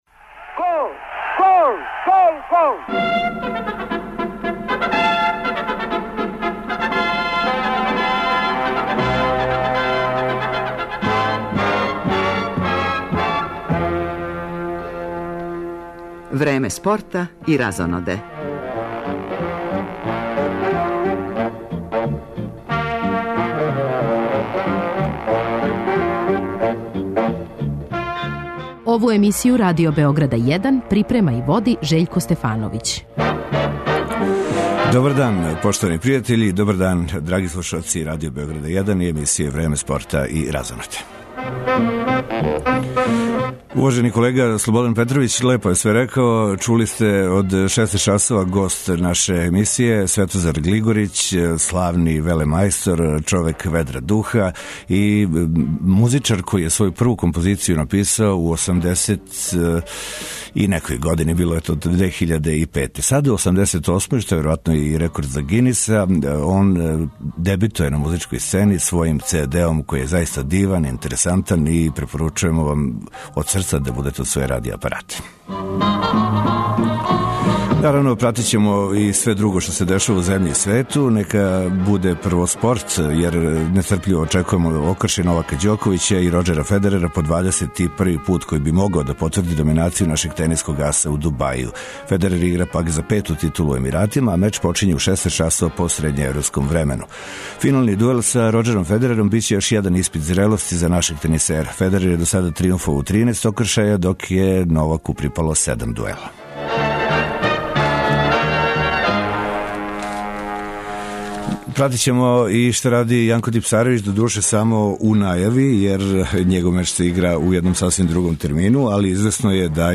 Данас почиње и фајнал фор истог такмичења за мушкарце, тако да ћемо пратити укључења с првог полуфиналног сусрета.
Ту је и тонски запис с недавног хуманитарног меча у дворани "Шумице" чији су актери били протагонисти филма "Монтевидео, Бог Те видео" и њихови гости.